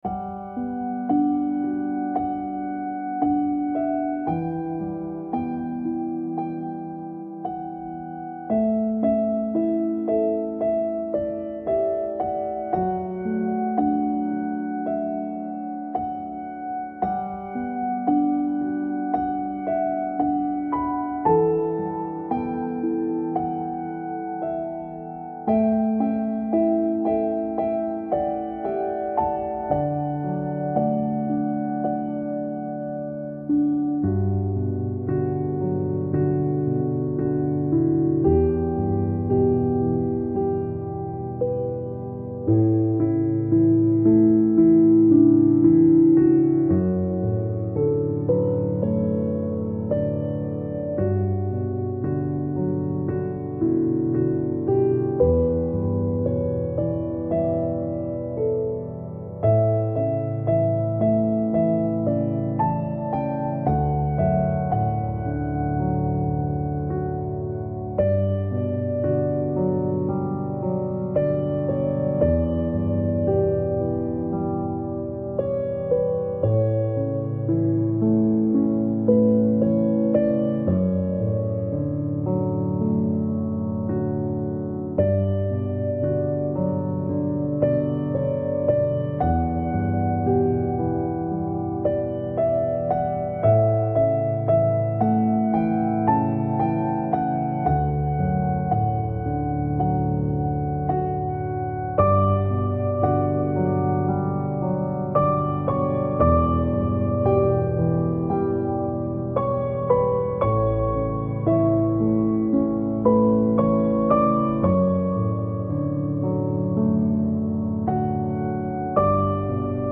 موسیقی کنار تو
آرامش بخش , پیانو , عاشقانه , عصر جدید , موسیقی بی کلام